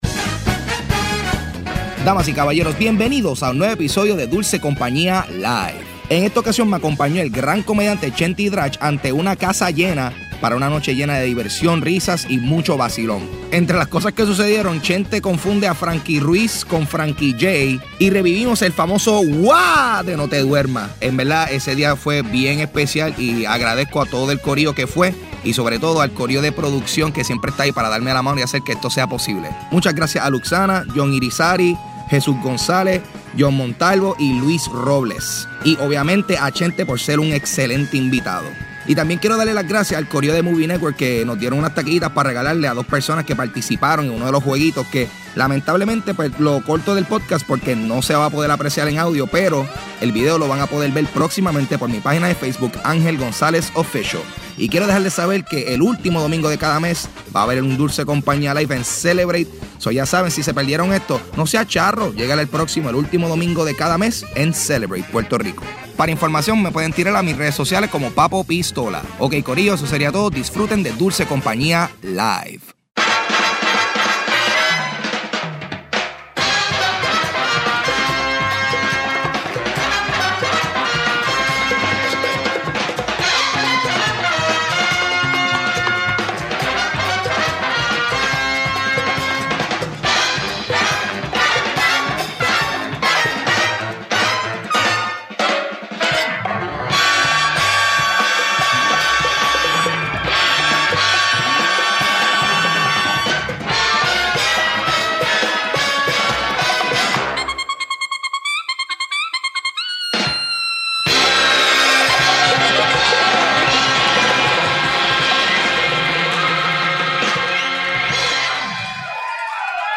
Live en Celebrate
Grabado el 27 de marzo de 2016 en Celebrate.